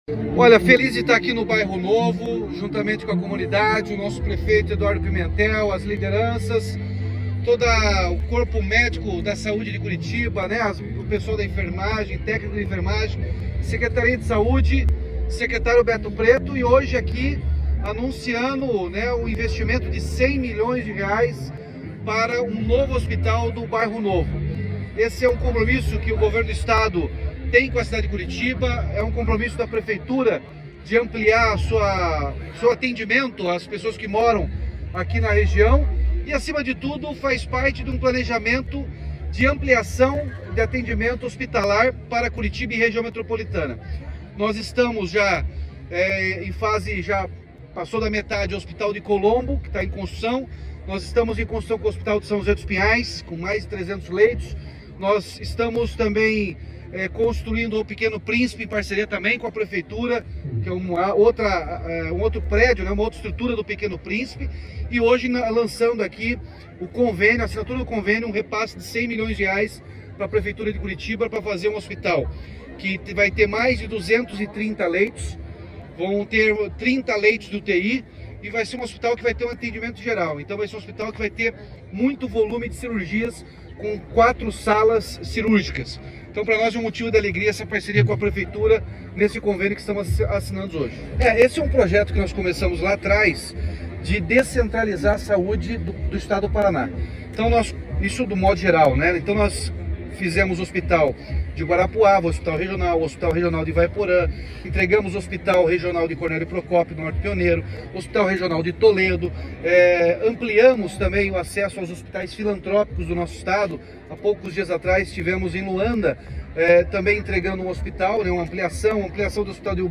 Sonora do governador Ratinho Junior sobre o anúncio do Hospital Bairro Novo, em Curitiba